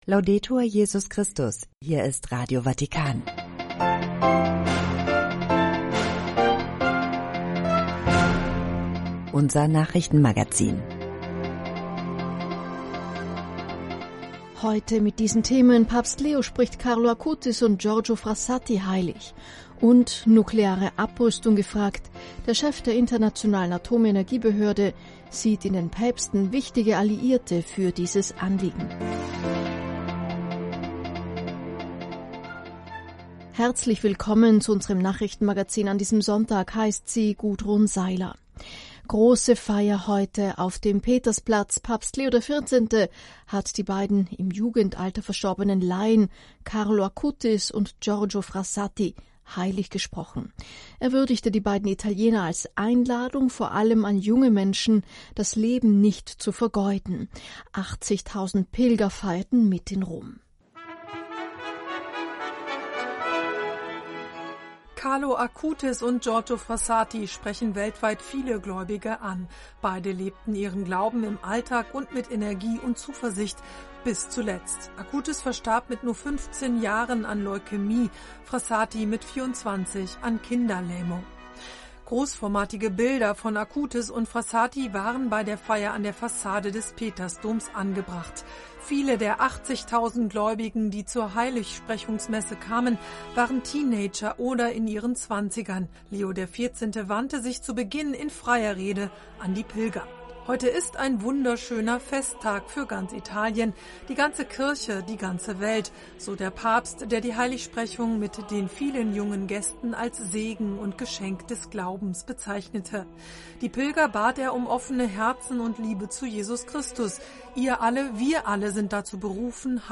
Treffpunkt Weltkirche - Nachrichtenmagazin (18 Uhr).